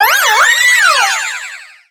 Cri de Diancie dans Pokémon X et Y.